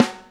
high snare p.wav